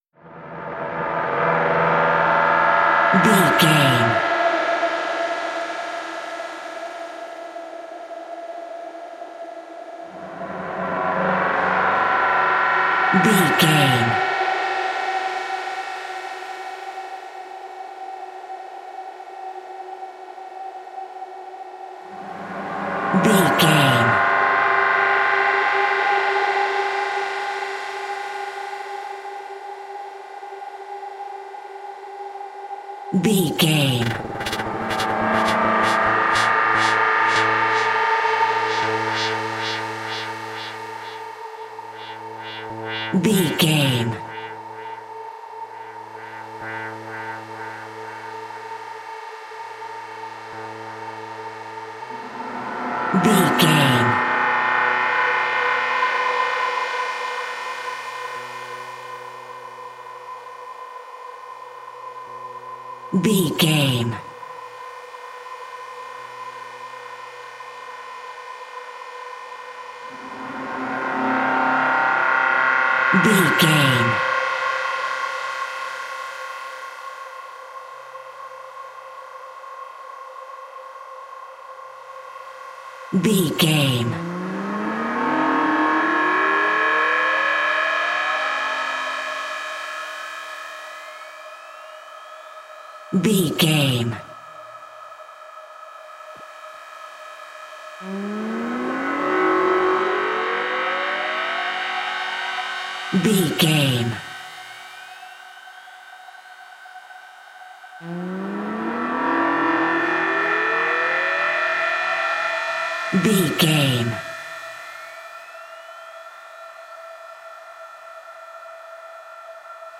Atonal
Slow
ominous
eerie
Horror synth
Horror Ambience
electronics
synthesizer